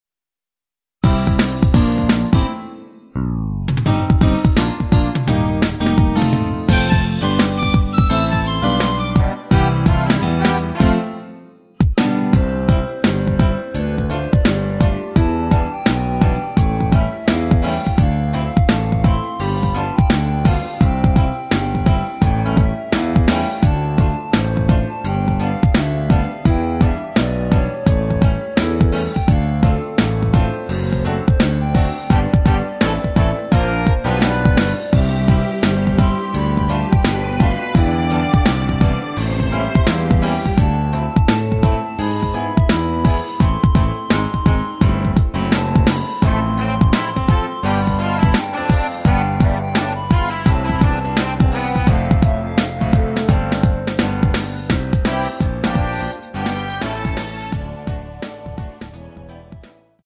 멜로디 포함된 MR 입니다.
멜로디 MR이라고 합니다.
앞부분30초, 뒷부분30초씩 편집해서 올려 드리고 있습니다.
중간에 음이 끈어지고 다시 나오는 이유는
C.C.M